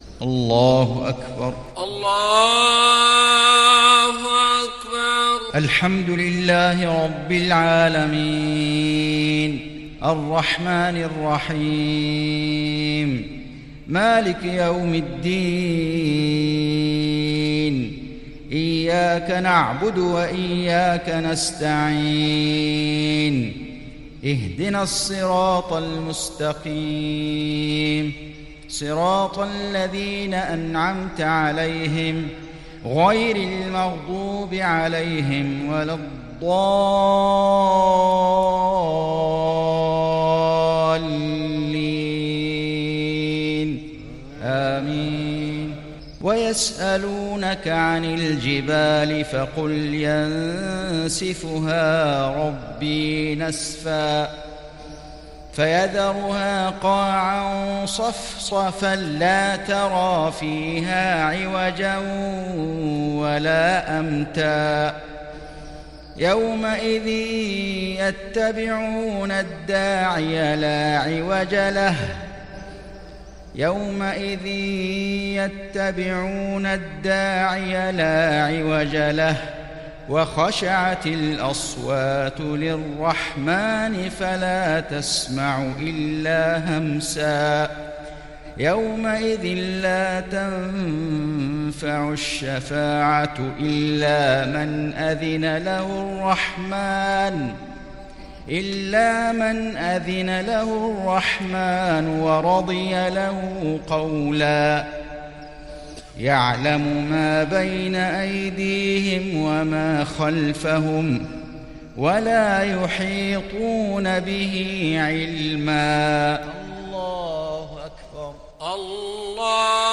صلاة المغرب للشيخ فيصل غزاوي 29 جمادي الأول 1441 هـ
تِلَاوَات الْحَرَمَيْن .